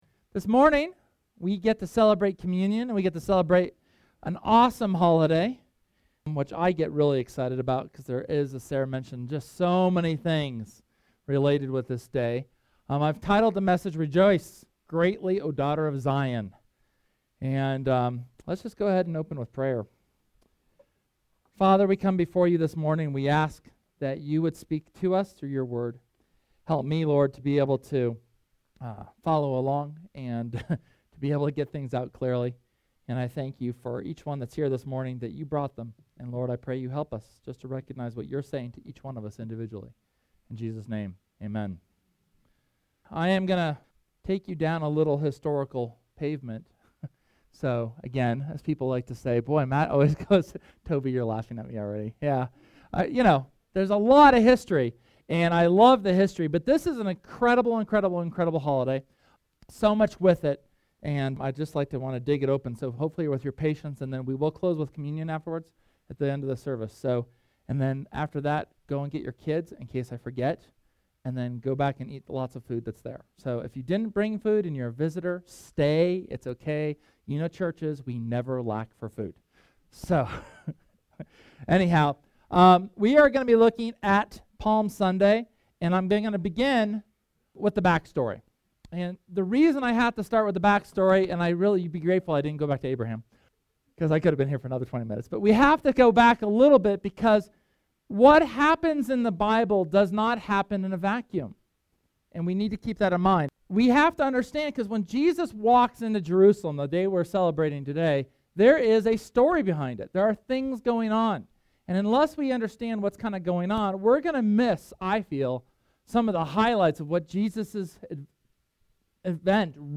SERMON: Palm Sunday 2014